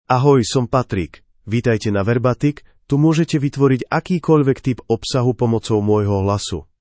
Patrick — Male Slovak (Slovakia) AI Voice | TTS, Voice Cloning & Video | Verbatik AI
Patrick is a male AI voice for Slovak (Slovakia).
Voice sample
Male
Patrick delivers clear pronunciation with authentic Slovakia Slovak intonation, making your content sound professionally produced.